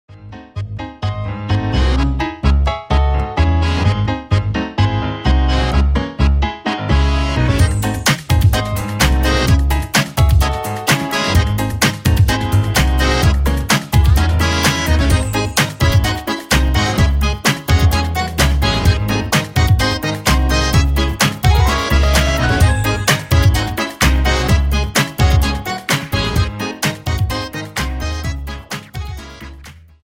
Dance: Tango 32